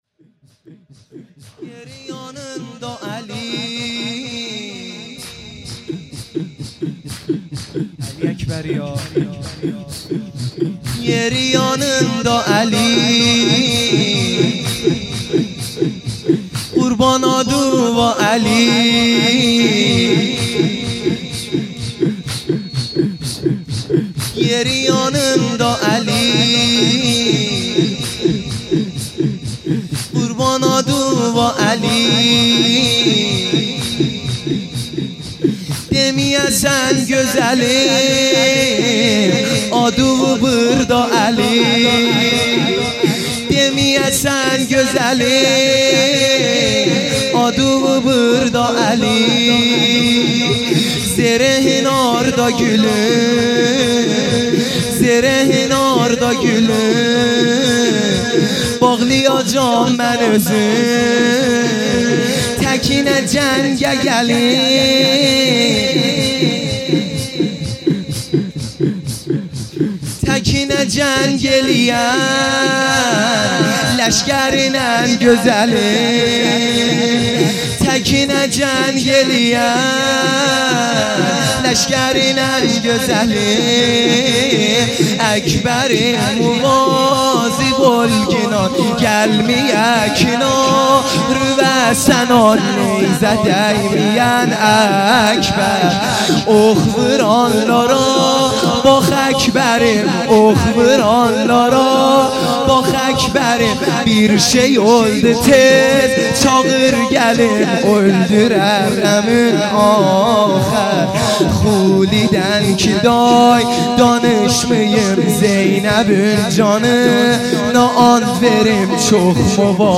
0 0 لطمه زنی
شب هشتم محرم الحرام ۱۳۹۶